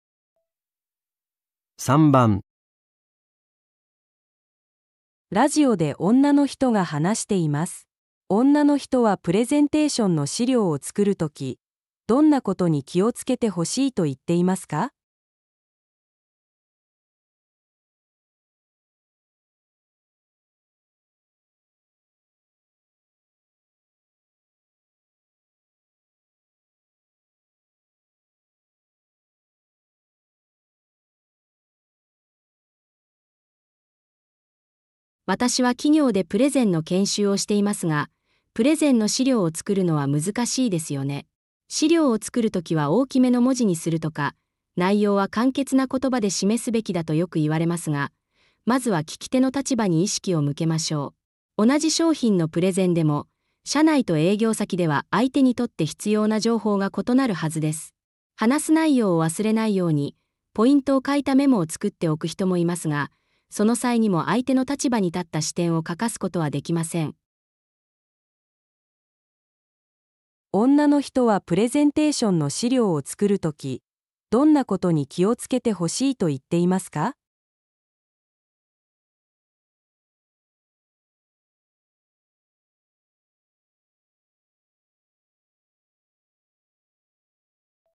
电台中一位女性正在讲话。